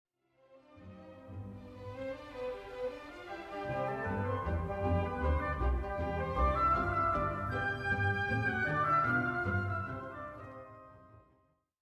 Well, enter second theme:
It’s more humble, more hesitating.